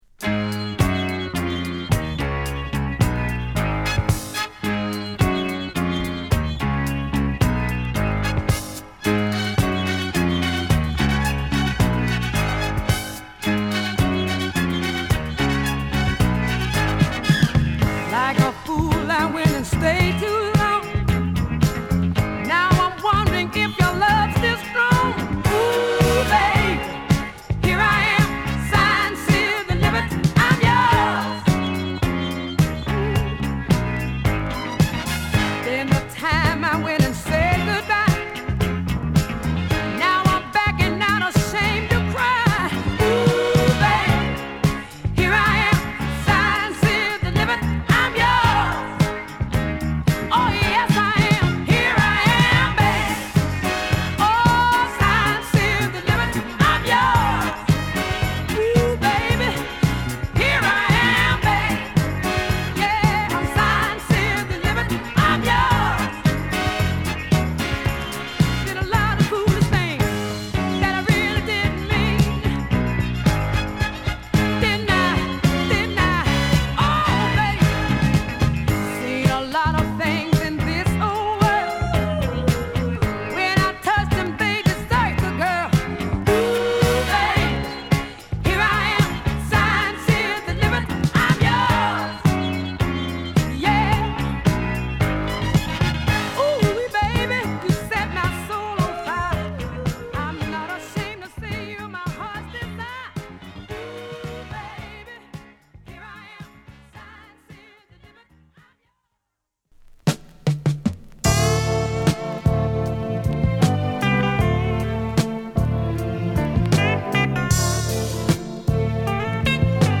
マイアミの乾いた空気を閉じ込めたグッド・ソウルを収録です！